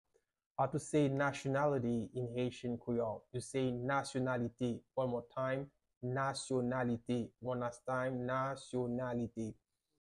How to say "Nationality" in Haitian Creole - "Nasyonalite" pronunciation by a native Haitian tutor
“Nasyonalite” Pronunciation in Haitian Creole by a native Haitian can be heard in the audio here or in the video below:
How-to-say-Nationality-in-Haitian-Creole-Nasyonalite-pronunciation-by-a-native-Haitian-tutor.mp3